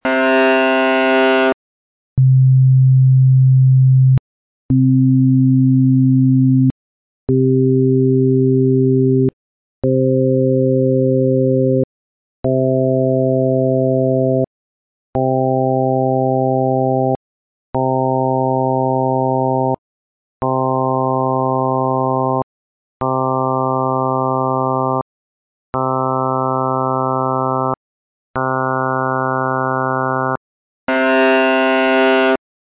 You will hear 13 notes each lasting 2 seconds. The first and the last are the bowed viola open C string. The second is the pure fundamental f0=131Hz.
In any note all harmonics have the same amplitude.
Although the tone of these notes is different -- they become brighter and have more 'edge' as harmonics are added -- you may accept that they all have the same pitch.
ViolaC-sequence-of-harmonics.mp3